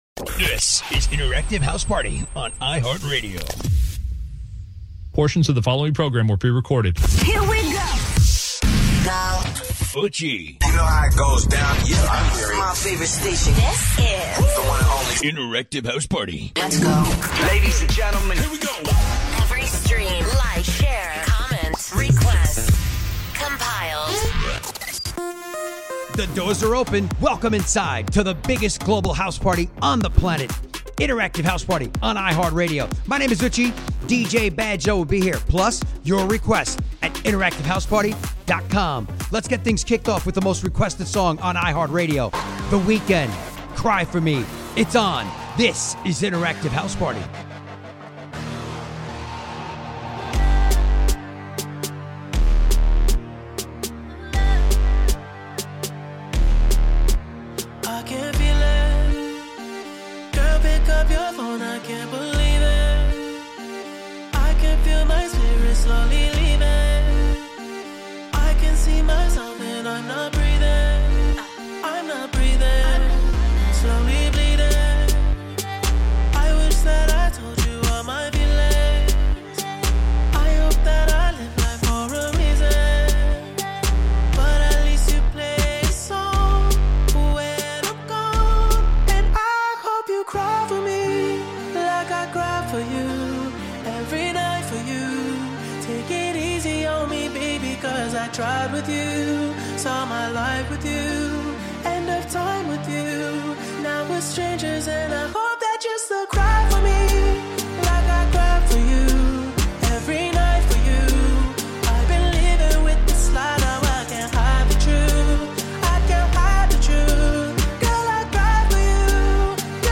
Music Commentary